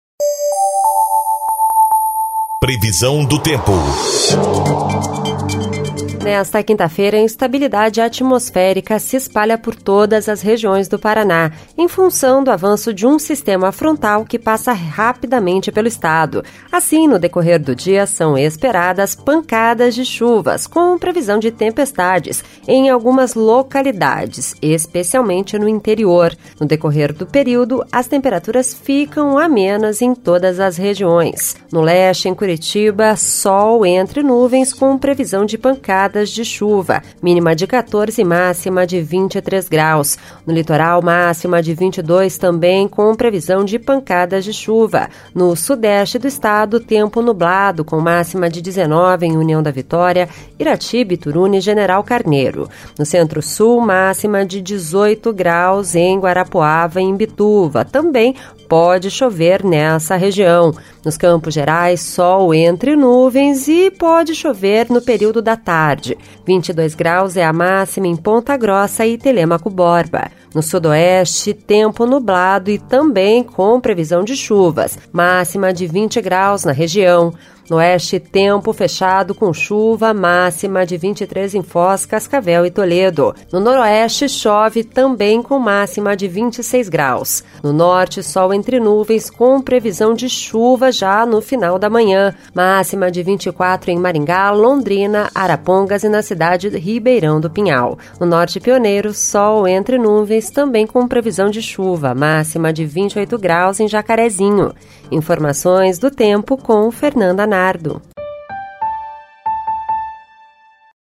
Previsão do Tempo (06/10)